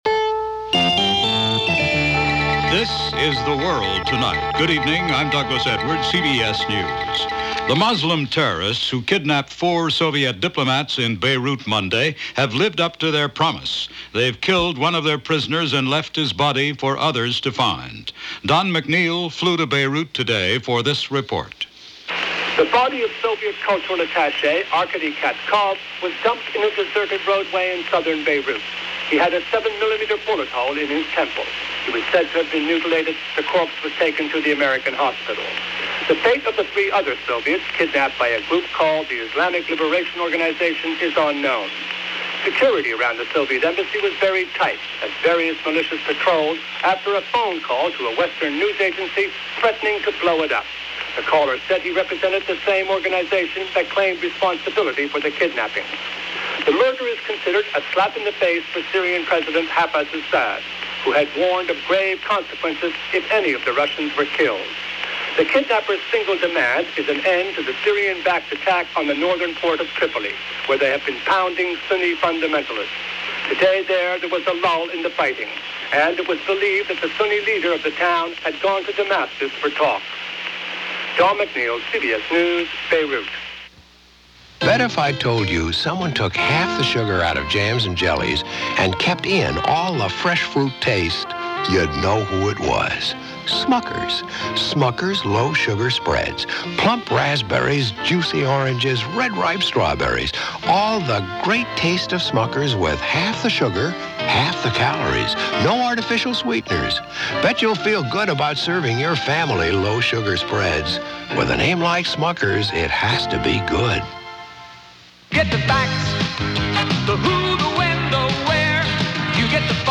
What a day – Along with the developing news from Beirut – this was October 2, 1985, as reported on the CBS Radio program The World Tonight and a special report on the death of Rock Hudson.